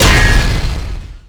bladeslice3.wav